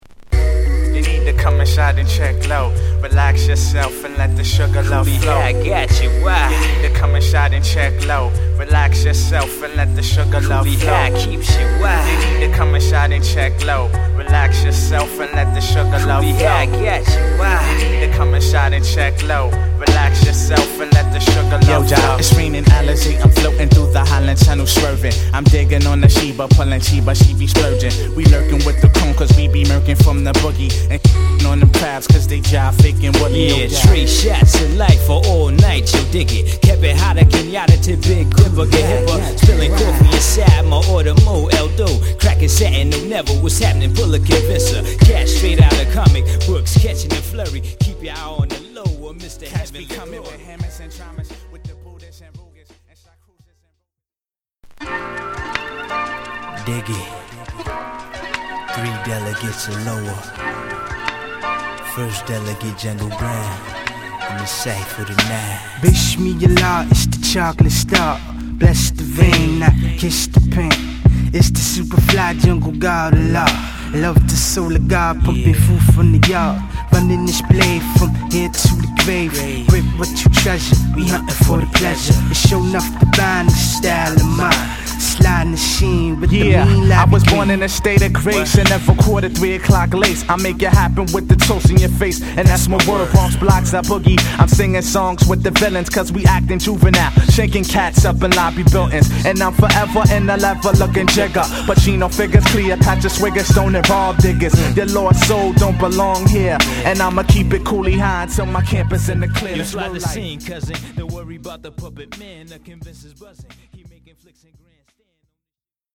浮遊感たっぷりのネタ使いに抜けの良いドラムが気持ち良いトラックで息の合った掛け合いを聞かせます！